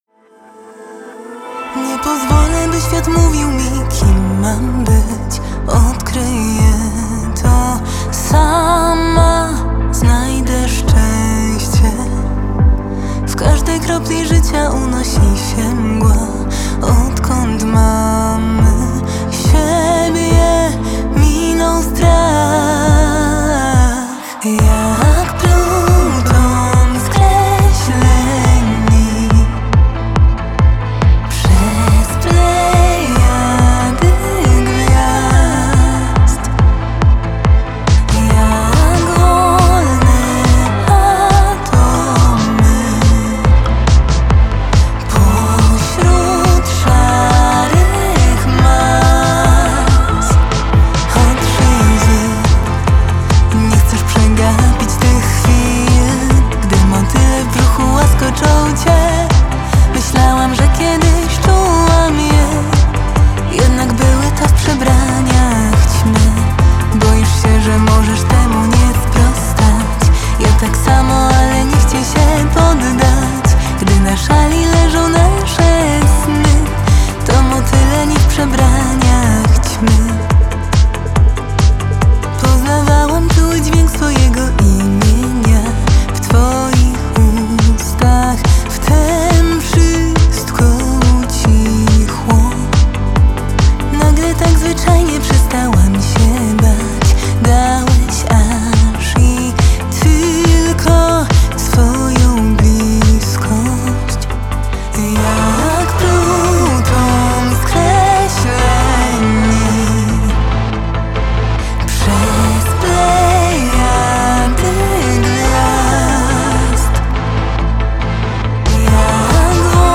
Singiel (Radio)
czyli hopecore dla wrażliwych.
rapowa partia